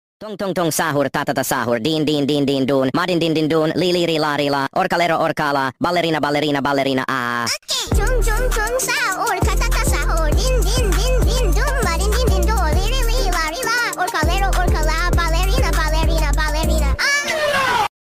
brainrot rap music